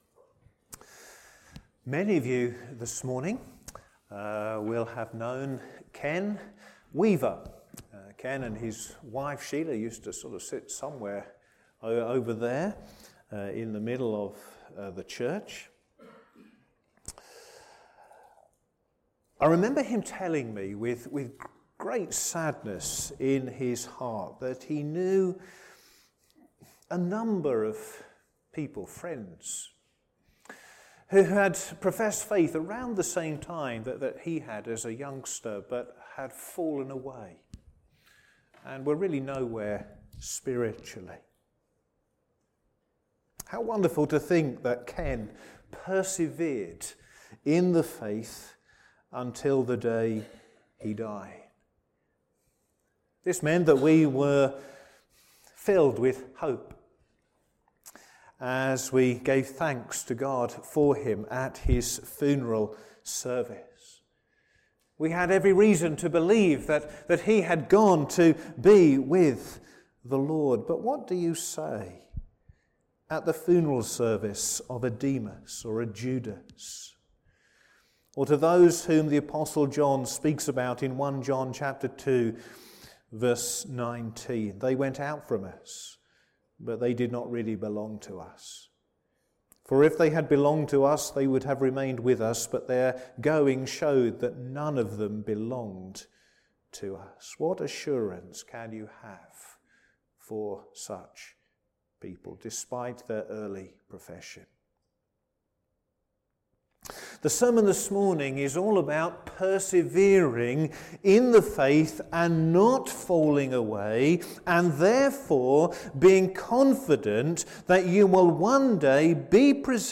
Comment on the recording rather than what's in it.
Service Morning